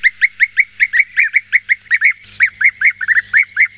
Guêpier d'Europe
Merops apiaster
guepier.mp3